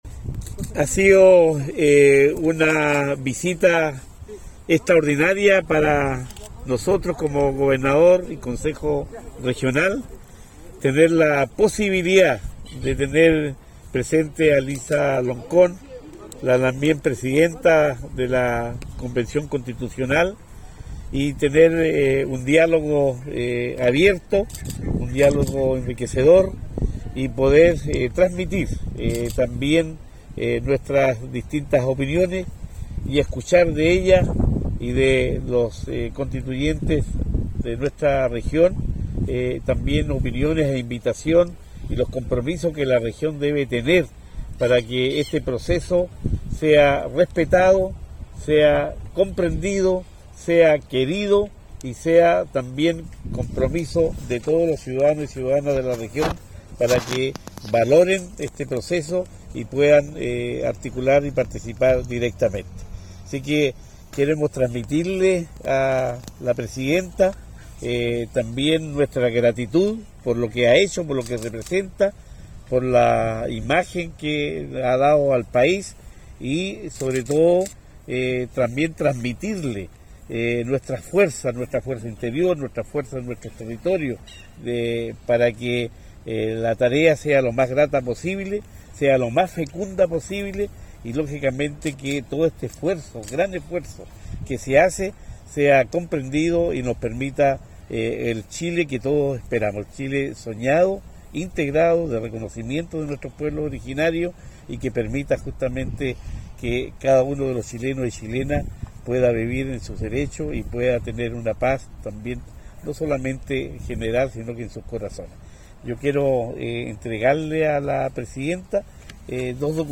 Cuña_Gobernador-Regional_encuentro-Elisa-Loncón.mp3